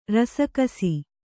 ♪ rasa kasi